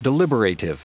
Prononciation du mot deliberative en anglais (fichier audio)
Prononciation du mot : deliberative